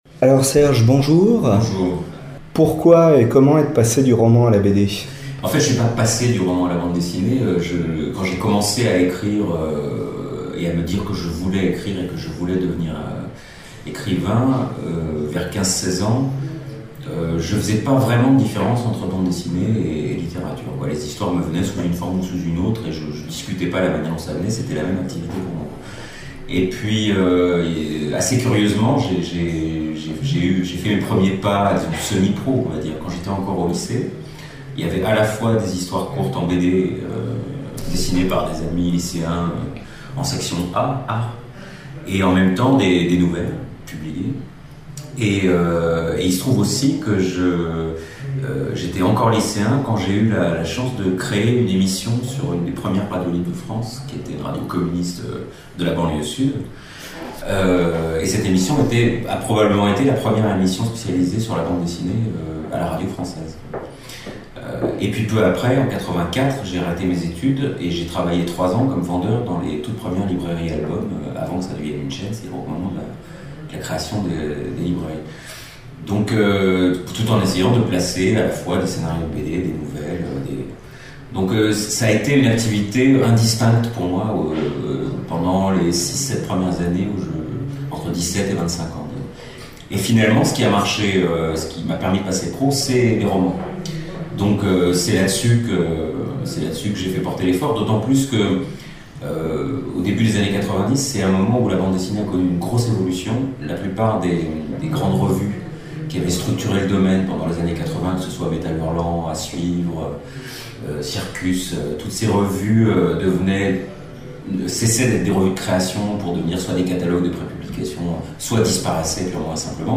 Voici l'enregistrement de l'interview.